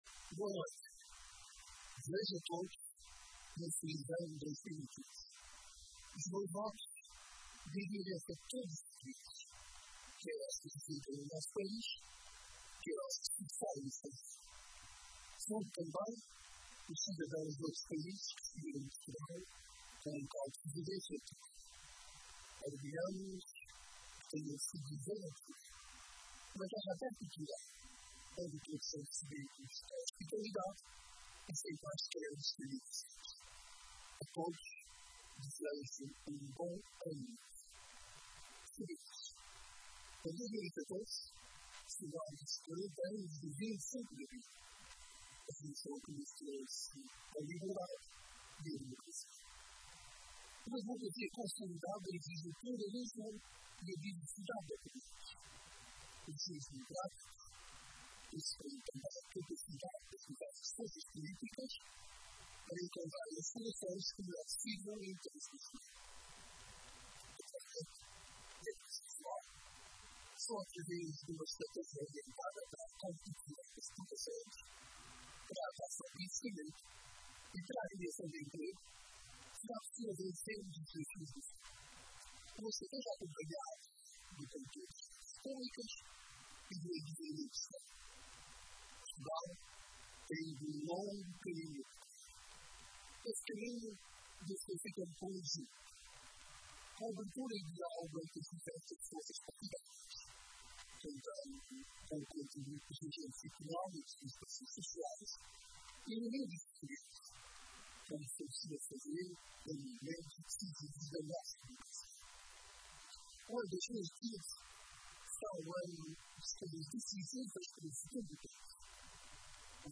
Na tradicional mensagem de Ano Novo, o chefe de Estado pediu aos partidos políticos para começarem a preparar o período pós-eleitoral, sublinhando que “não é só no dia a seguir às eleições que se constroem soluções governativas estáveis”.